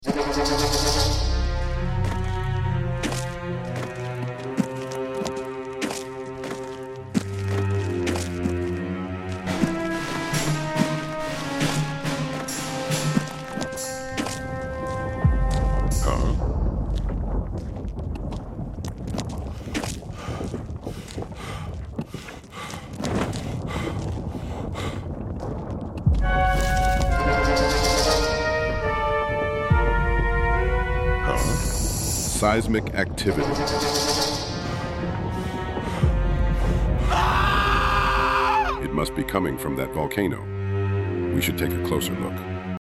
Classic Retro Sci-fi Action Adventure Comedy Journey
Soundtrack Done in FL Studio
Voice from ElevenLabs